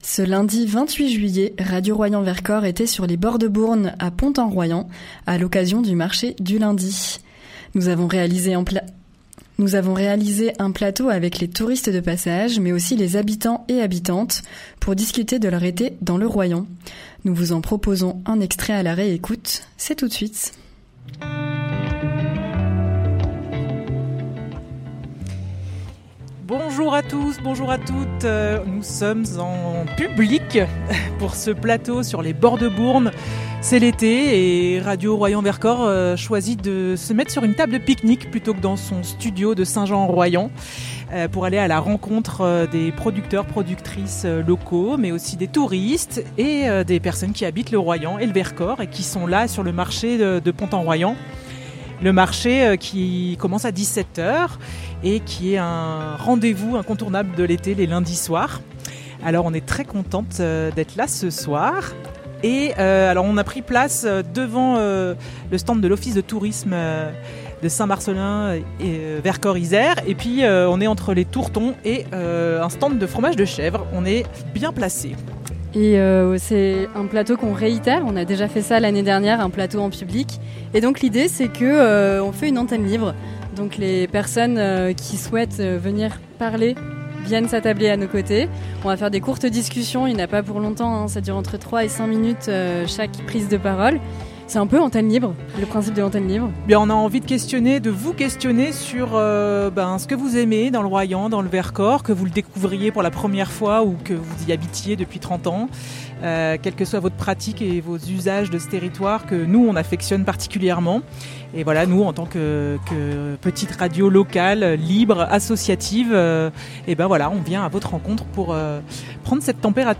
Un plateau radio depuis les bords de Bourne à Pont-en-Royans, pendant le marché estival du lundi ! Radio Royans Vercors a tendu ses micros aux touristes de passages, mais aussi aux habitants et habitantes pour leur parler de leur été dans le Royans Vercors.